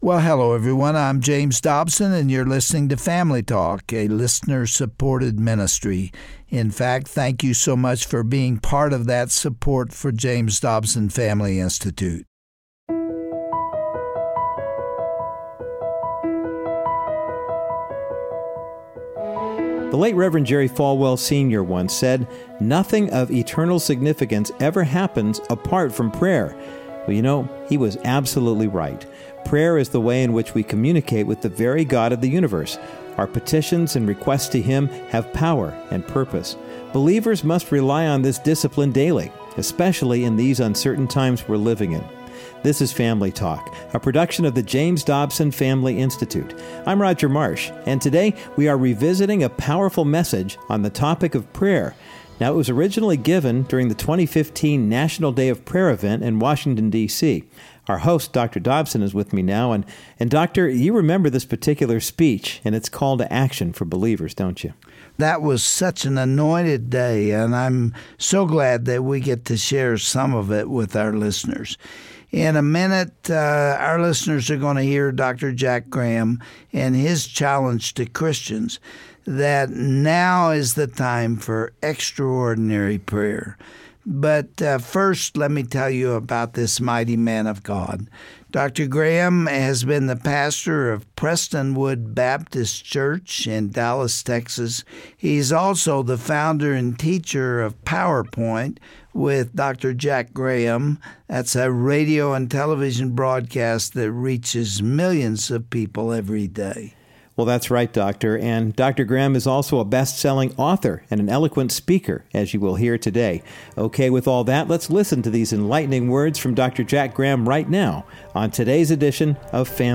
What is the impact of our petitions to God? Pastor Jack Graham emphasizes what our personal and national posture should be toward God, and why we mustn't lose heart in our prayer requests.
Host Dr. James Dobson